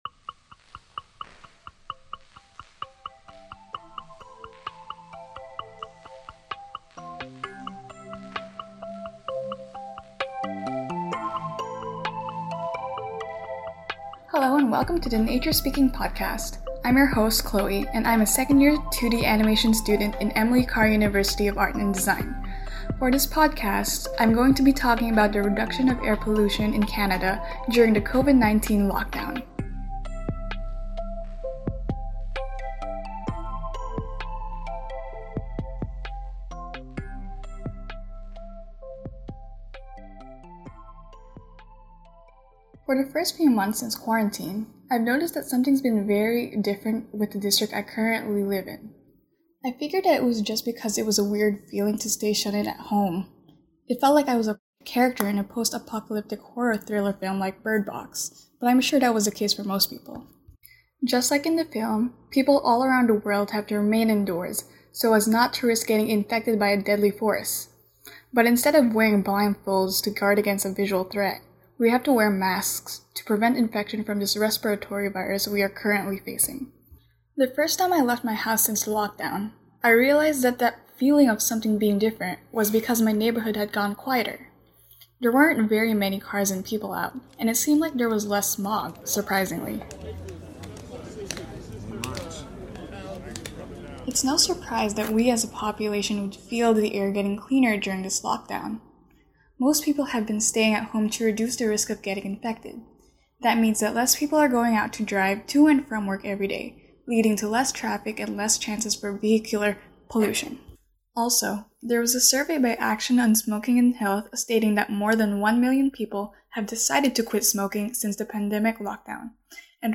Audio non-musical